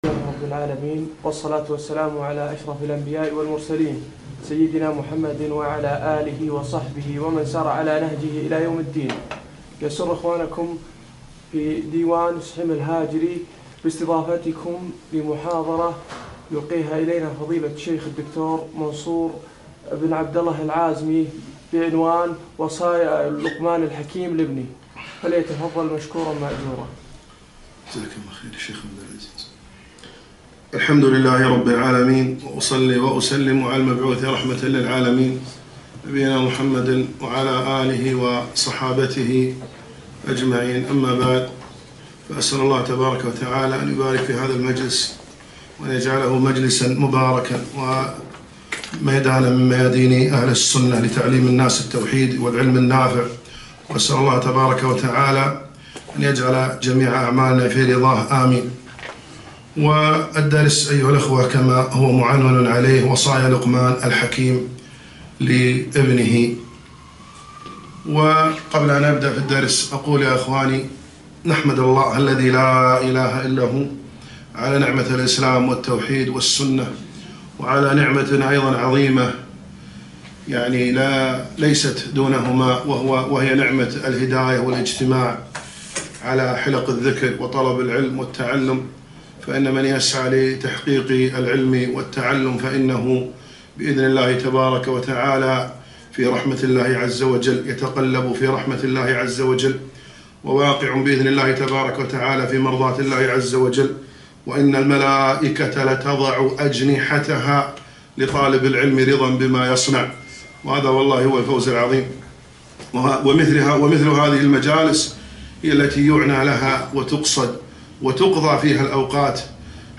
محاضرة - وصايا لقمان الحكيم لابنه